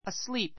asleep A2 əslíːp ア ス り ー プ 形容詞 眠 ねむ って be asleep be asleep 眠っている ⦣ 名詞の前にはつけない.